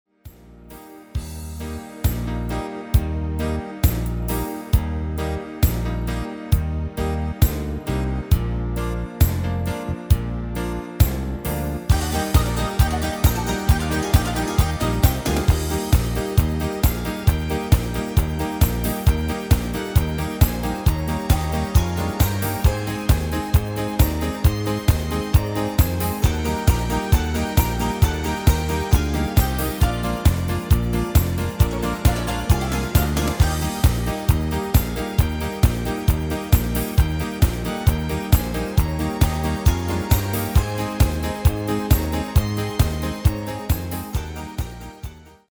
Demo/Koop midifile
Genre: Duitse Schlager
- GM = General Midi level 1
- Géén vocal harmony tracks